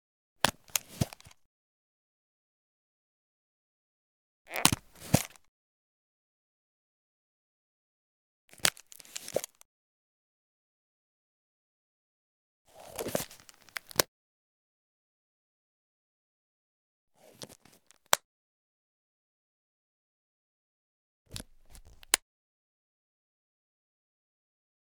Camera Hard Leather Protective Case Remove Camera Sound
household